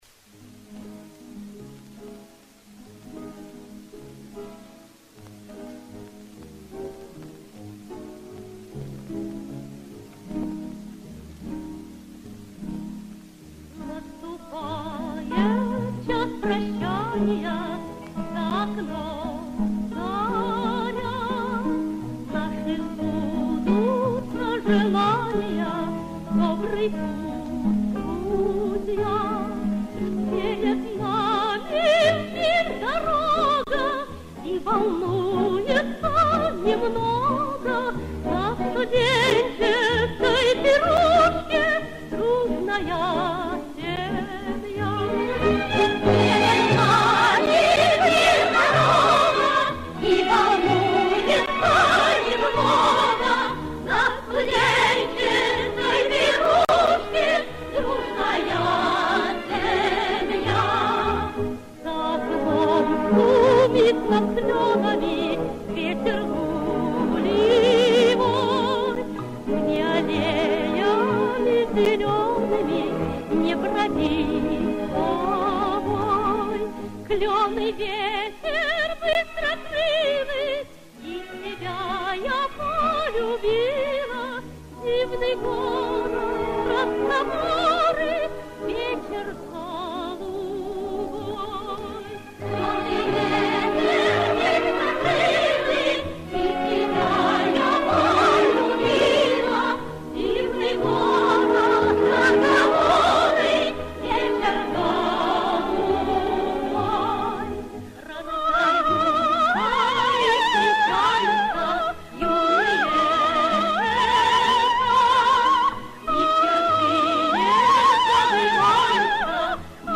Популярные песни c сопровождением баяна